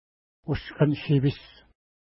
Pronunciation: u:skən-ʃi:pi:s
Pronunciation